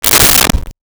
Close Door 01
Close Door 01.wav